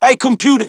synthetic-wakewords
ovos-tts-plugin-deepponies_Franklin_en.wav